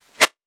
weapon_bullet_flyby_09.wav